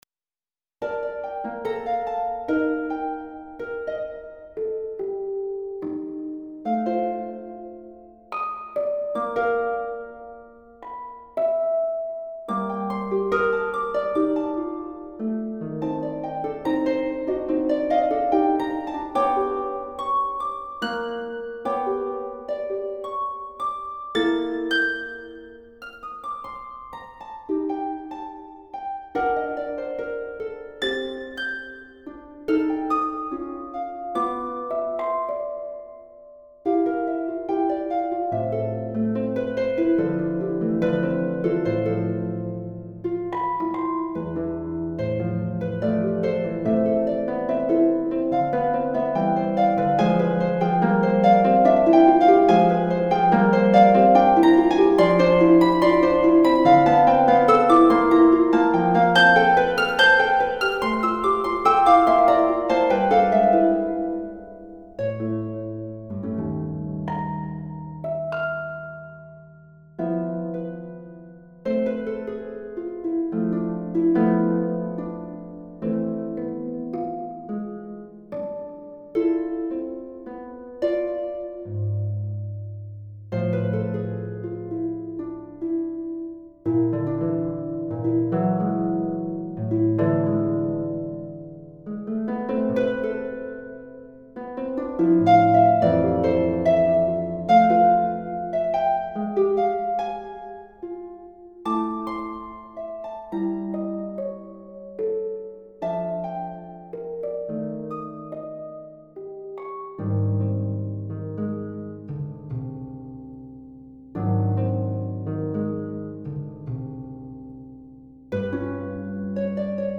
Persephone Computer realisation